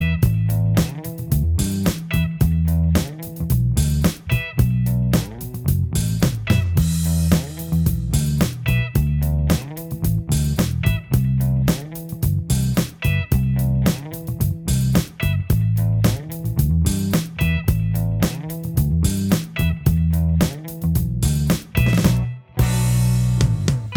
No Lead Guitars Soft Rock 4:45 Buy £1.50